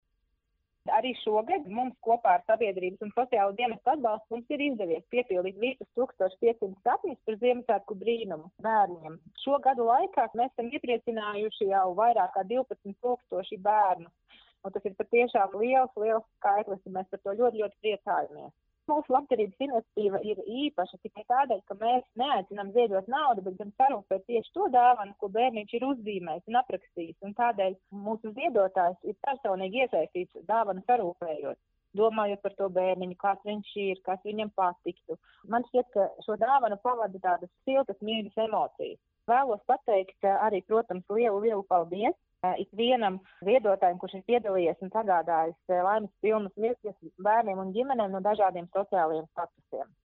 RADIO SKONTO Ziņās par sarūpēto Laimas labdarības namiņā